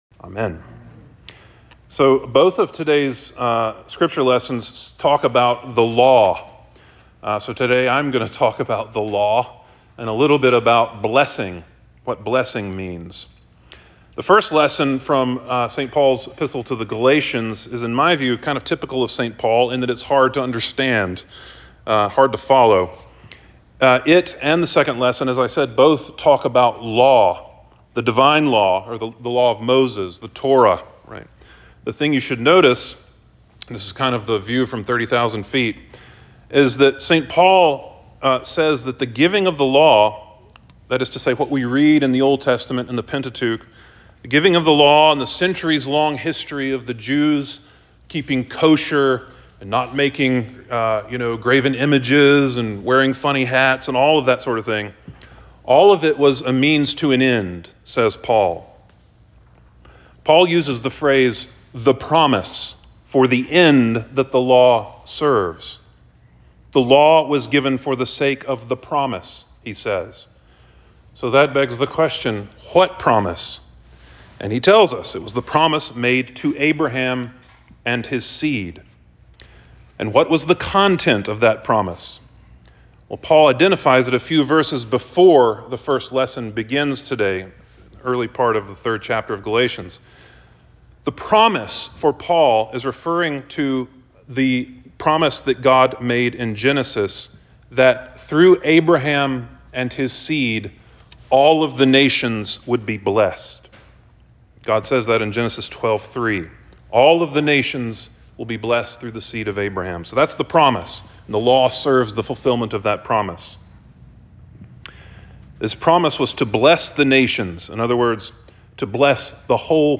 Sermon for the Thirteenth Sunday After Trinity 08.25.24